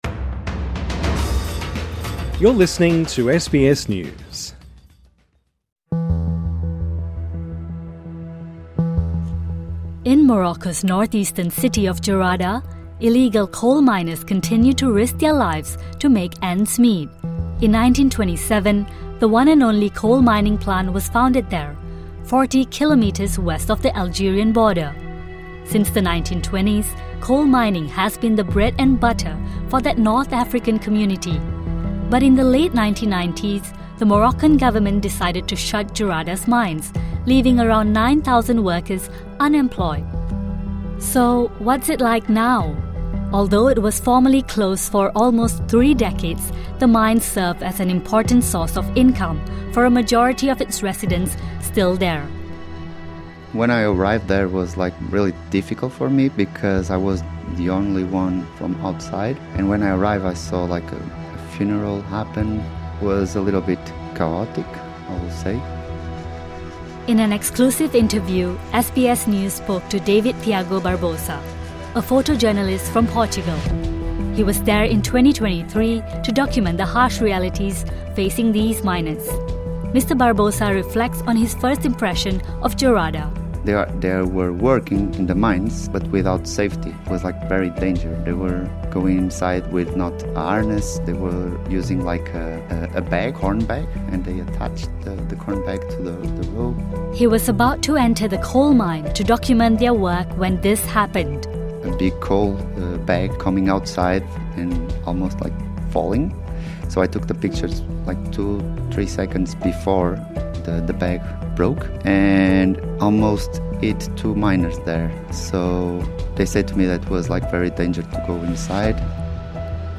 In one of Morocco's impoverished coal towns, illegal miners continue to risk their lives to make ends meet. In an exclusive sit-down interview with SBS News, a photojournalist has shared the harsh realities he witnessed of the miners in Jerada. Ahead of the International Mining Congress in Morocco, experts are calling for better health and safety regulations to be implemented in the country.